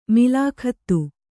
♪ milākhattu